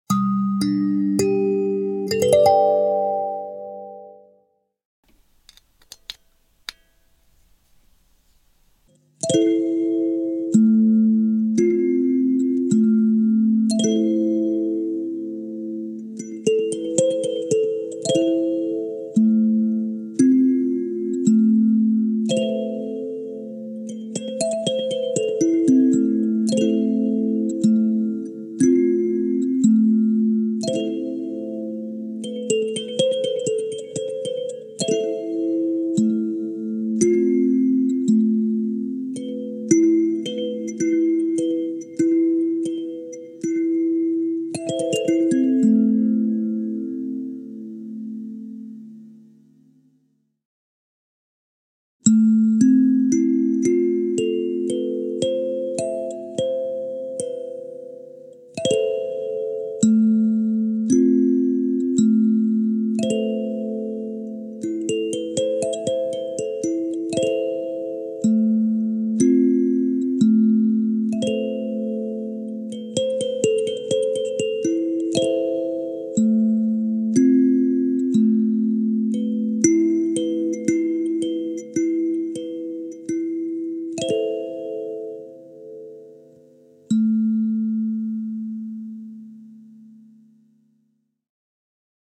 Kalimba électronique • 9 notes • La mineur
• 9 lamelles accordées en La Mineur, pour un son fluide et harmonieux
• Son harmonieux, parfait pour la relaxation et les voyages sonores
• Bois de qualité, offrant une belle résonance naturelle
Kalimba-Electro-La-mineur.mp3